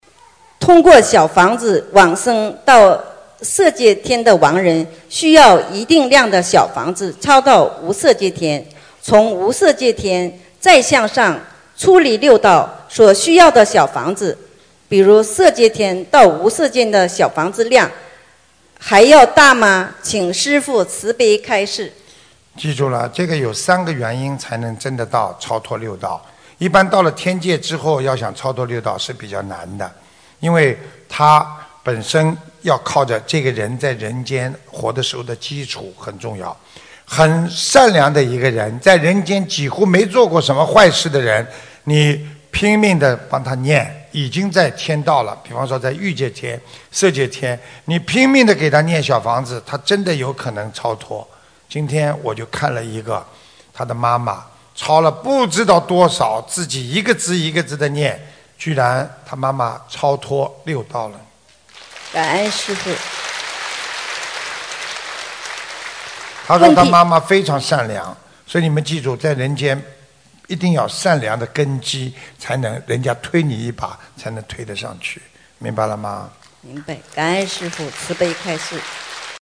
在人间要有善良的根基，过世后才能靠小房子超脱六道┃弟子提问 师父回答 - 2017 - 心如菩提 - Powered by Discuz!